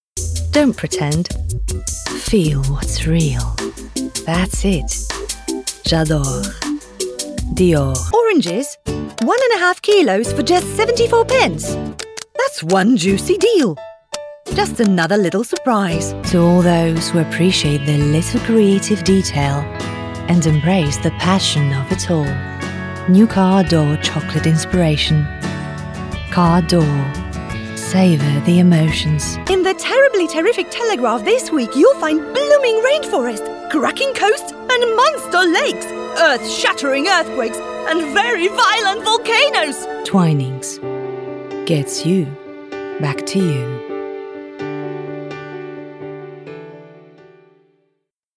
English Commercial Showreel
Female
English with International Accent
Friendly
Upbeat
Warm